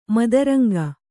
♪ madaranga